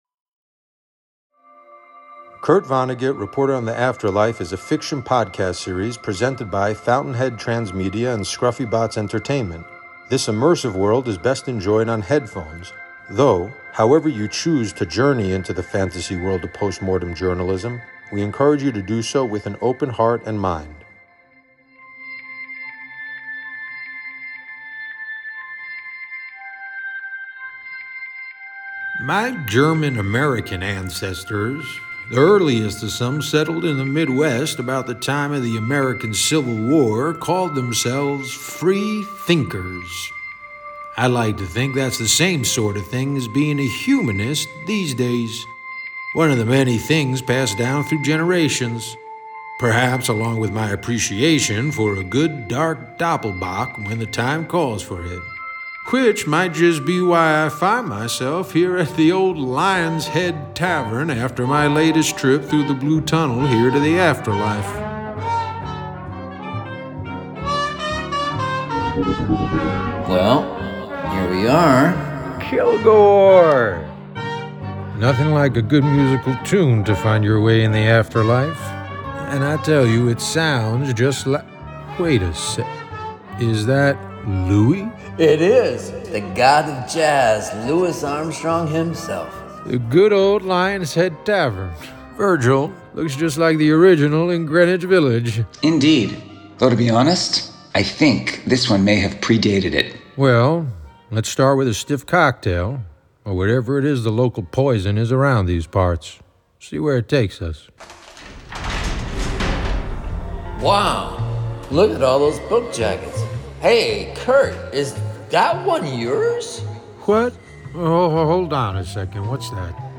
[00:00:32] Speaker C: Kurt Vonnegut, reporter on the Afterlife is a fiction podcast series presented by Fountainhead, Transmedia, and Scruffy Bots Entertainment. This immersive world is best enjoyed on headphones, though however you choose to journey into the fantasy world of postmortem journalism, we encourage you to do so with an open heart and mind.